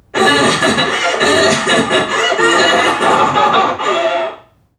NPC_Creatures_Vocalisations_Robothead [50].wav